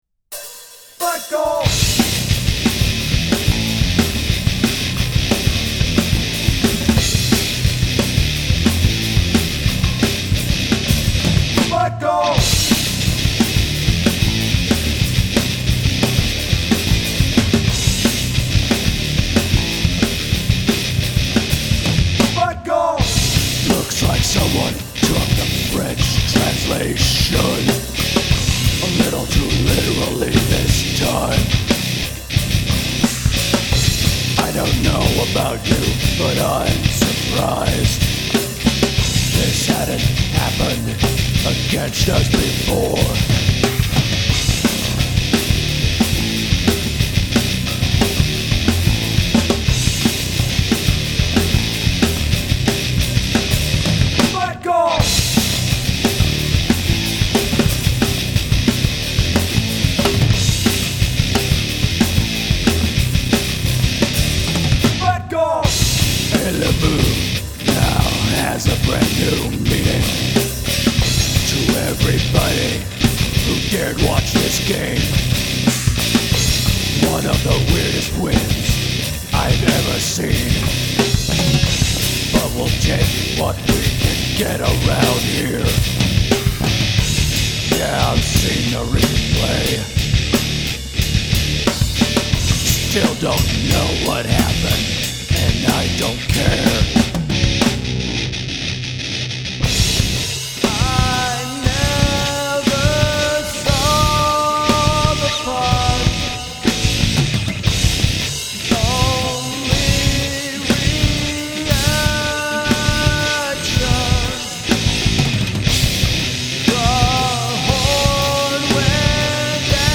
Now BACK TO THE METAL.
Also, it was about time for me to do the “wall of guitar” thing again, wasn’t it? At its peak, there are seven guitar tracks happening at the same time.